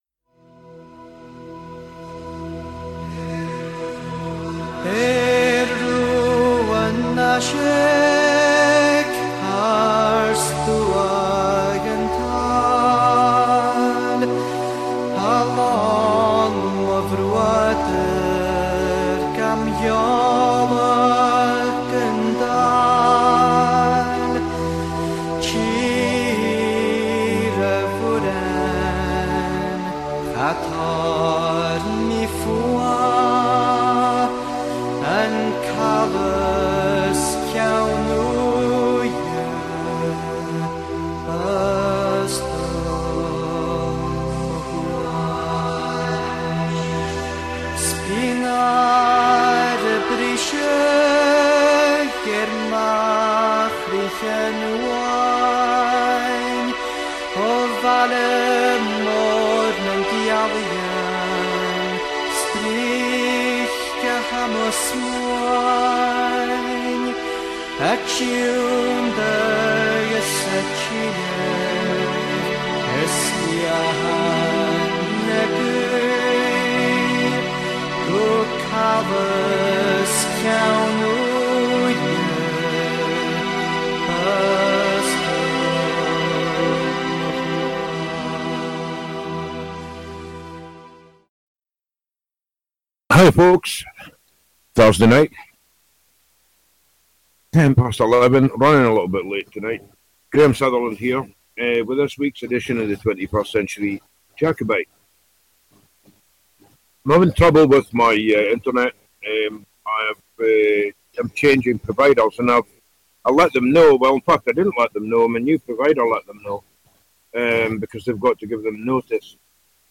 Callers are welcome to contribute. This weekly radio show broadcasts live every Thursday from Inverness, Scotland, transmitting real, uncensored and unsanitized philosophy, news and perspectives.